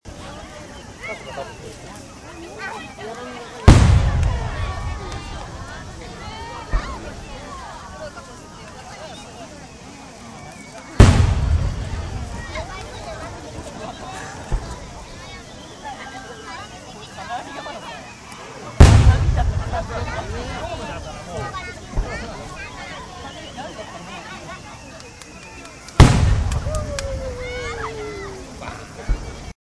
花火はじまった